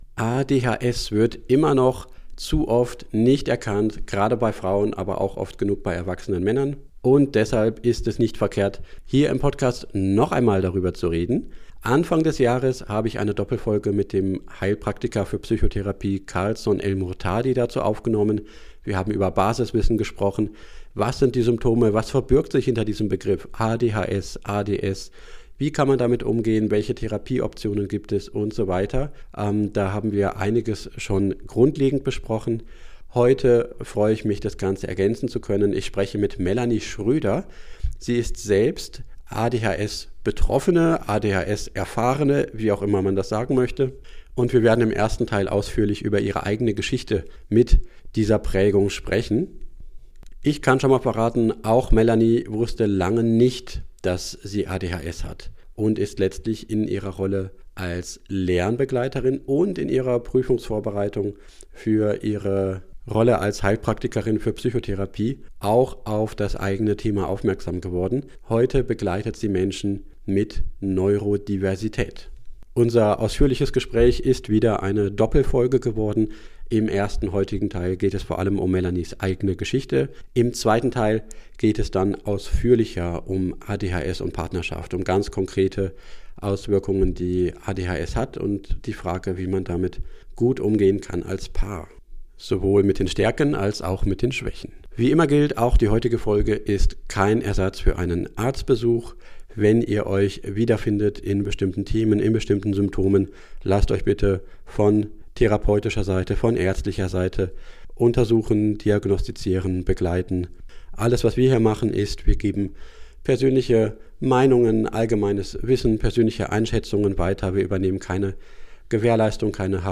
Keine Werbung: Für die Aufnahme und Verbreitung des Interviews erhalte ich keine Gegenleistung.